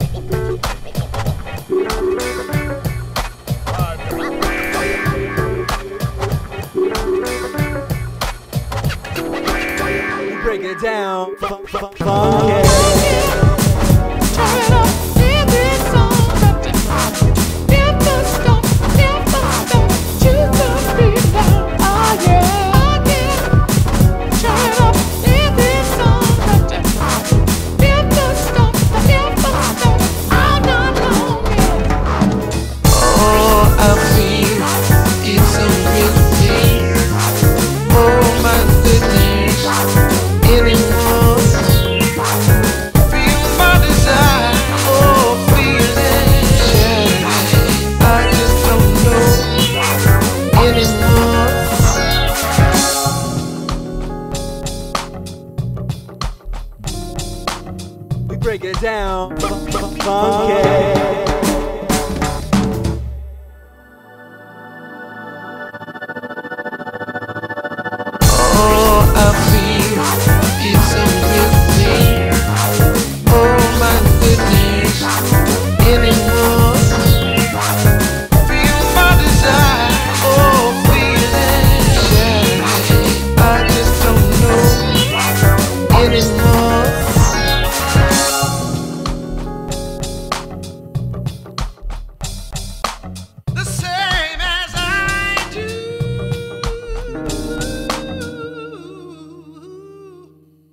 BPM48-190
Audio QualityMusic Cut